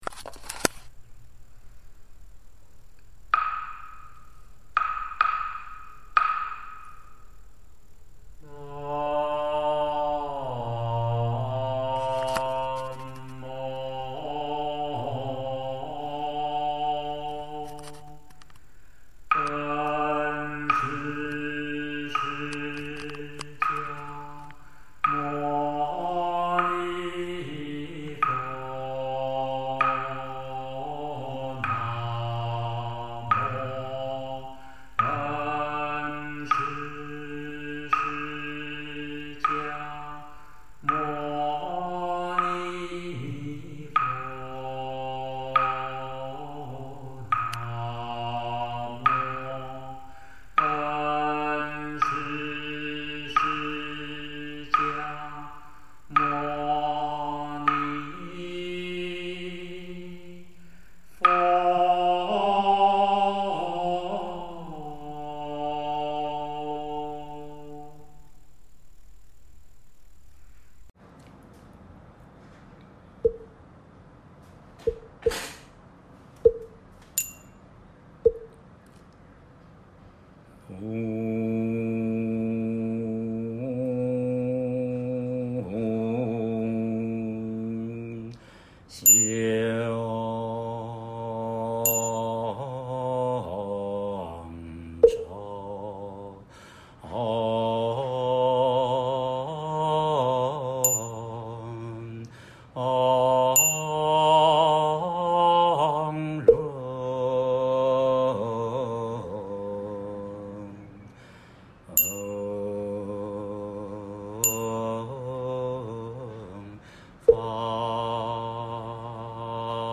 4、《悲增法会》梵呗仪轨及教学音档 佛教正觉同修会_如来藏网